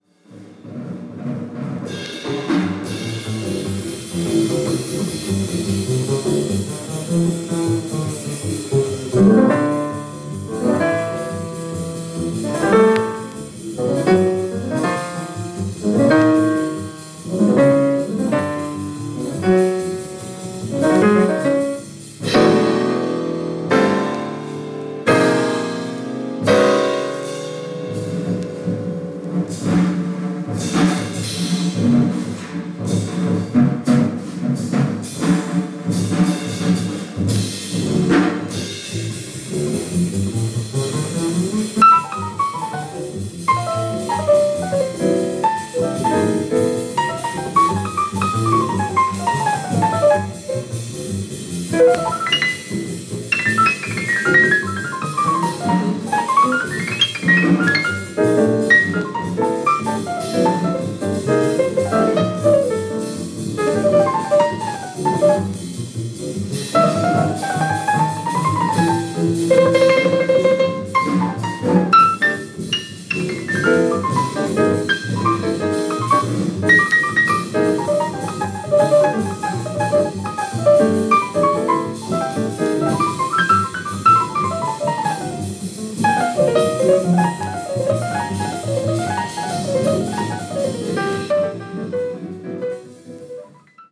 鍵盤奏者
これからの季節にのんびりどうぞ。